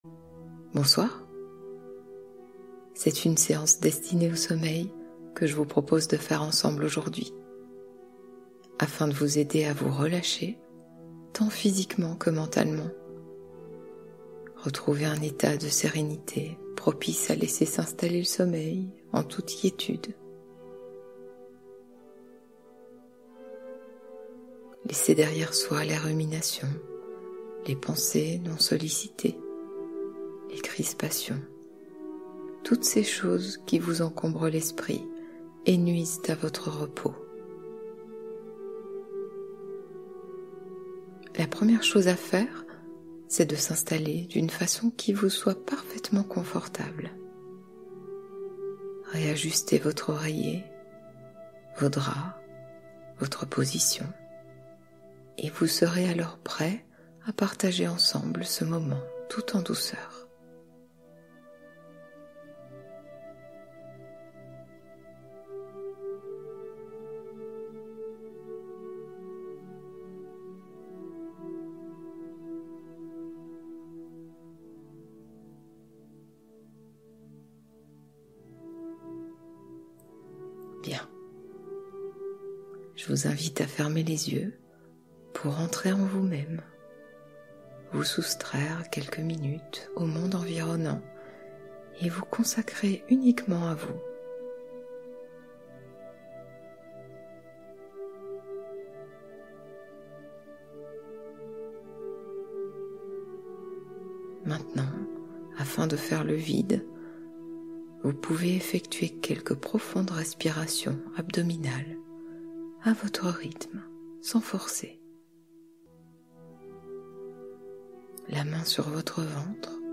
De la mer au sommeil : hypnose somnifère